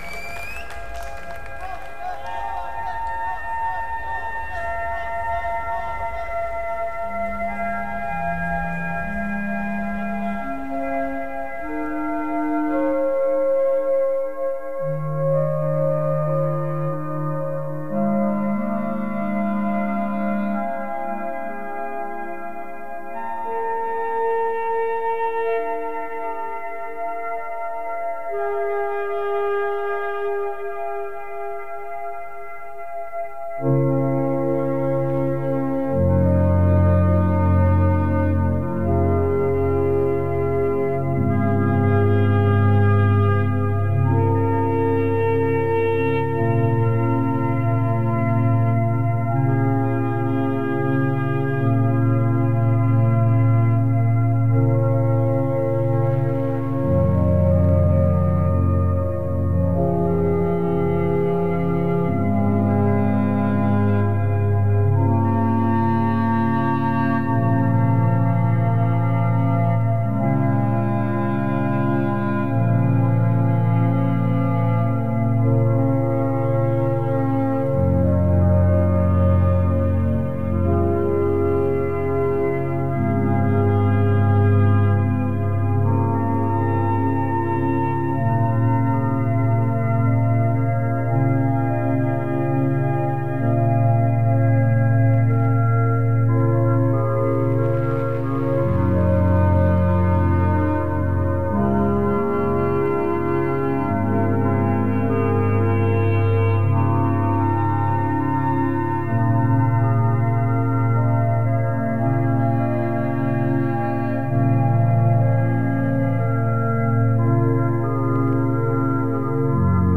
Liveset 1.
event Roskilde Festival